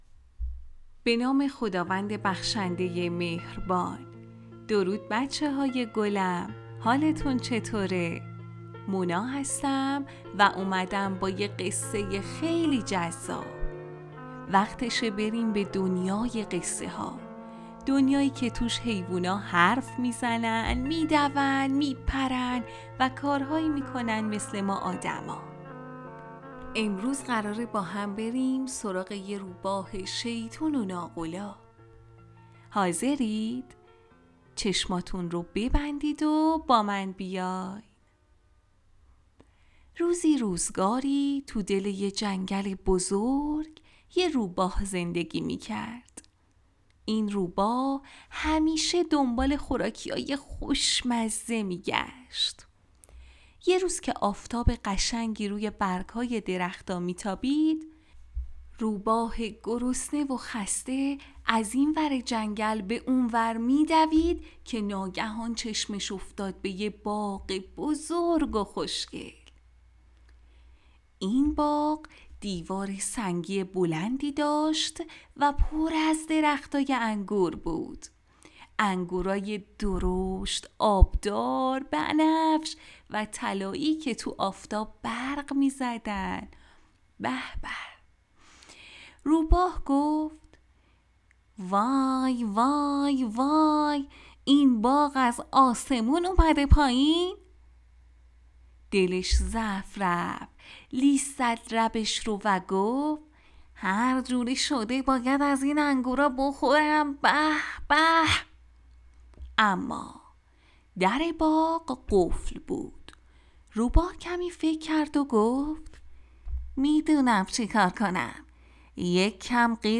هر هفته یک قصه